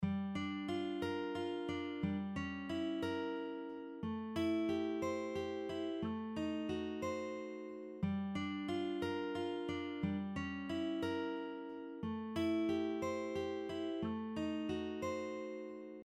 This is a common jazz progression that goes 2 min – 5(7) – 3 min – 6 (7).
Diminished-chord-progressions-on-guitar-4-.mp3